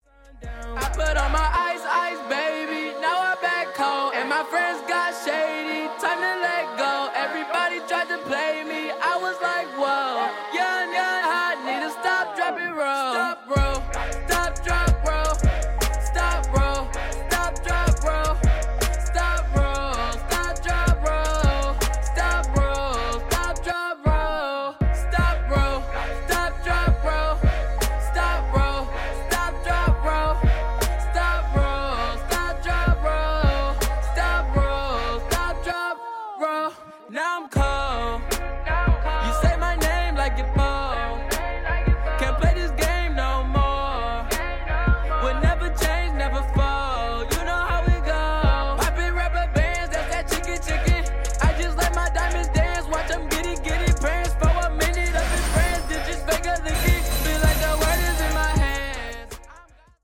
Genres: Hip hop, trap, cloud rap